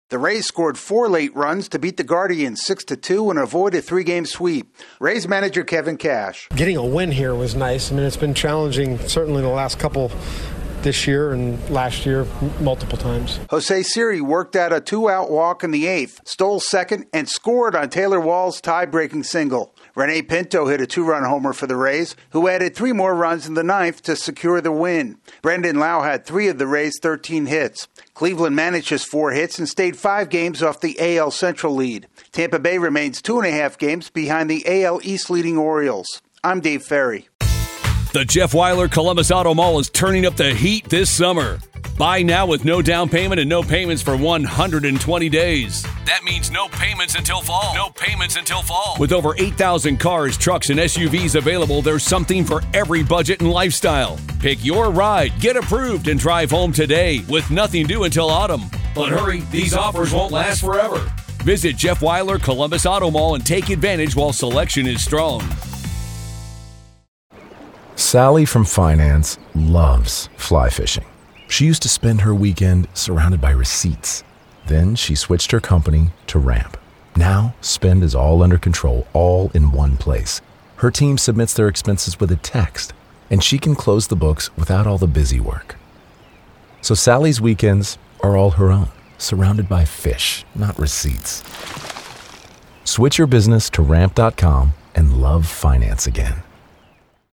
The Rays salvage the finale of their series in Cleveland. AP correspondent